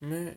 男人的叹息声音
描述：男人的叹息声音
标签： 叹息 男性 语音
声道单声道